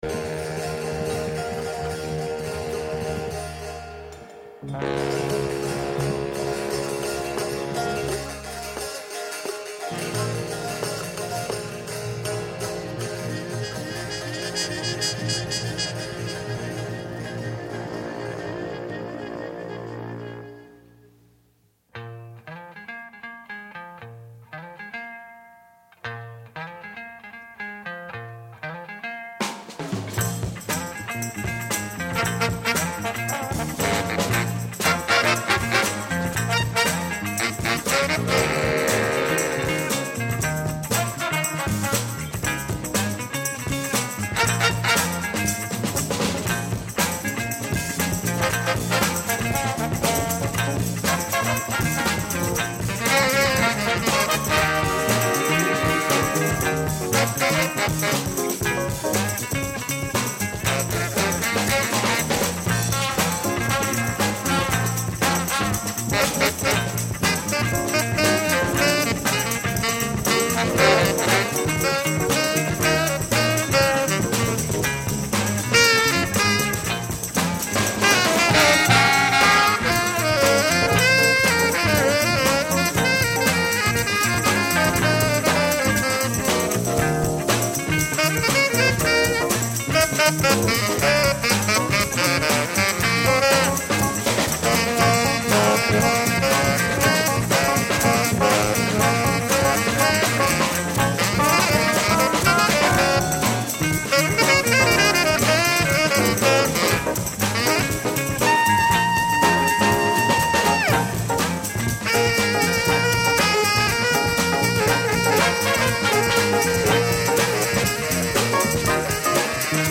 Today’s explorations will lean toward jazz.